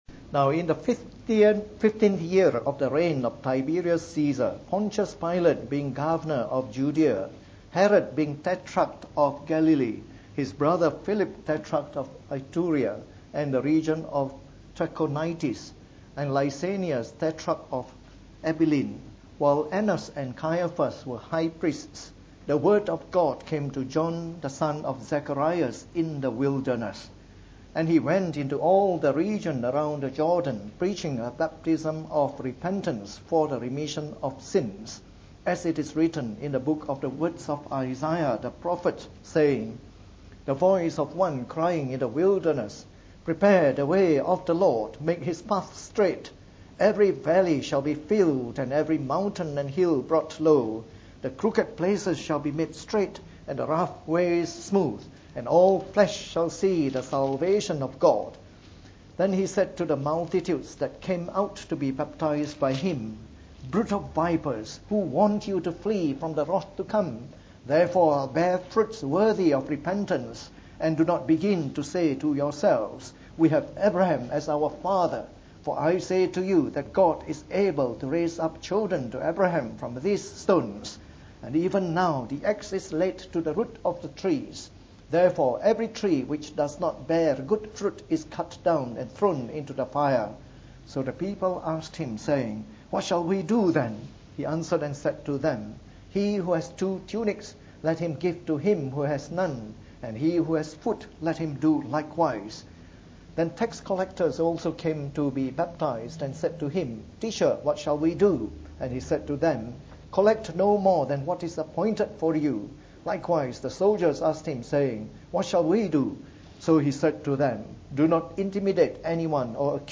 From our series on the “Gospel According to Luke” delivered in the Evening Service.